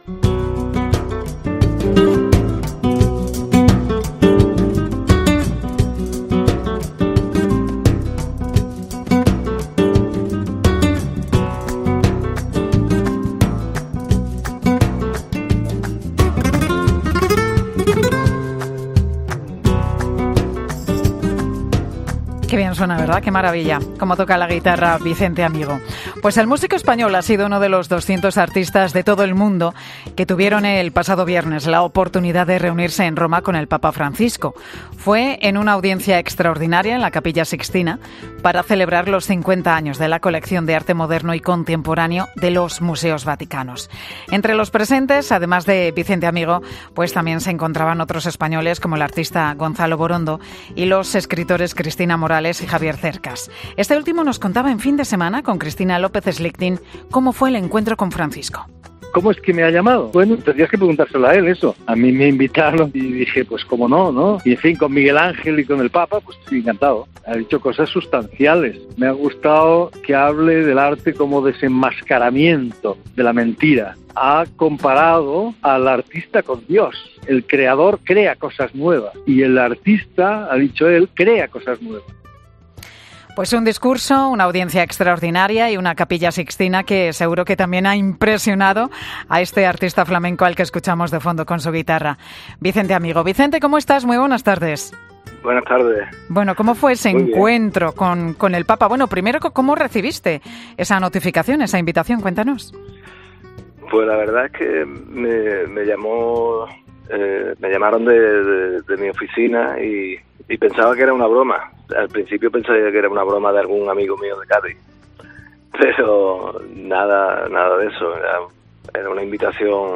El guitarrista flamenco ha contado en 'Mediodía COPE' cómo ha sido la audiencia extraordinaria con 200 artistas por los 50 años de la Colección de Arte Moderno y Contemporáneo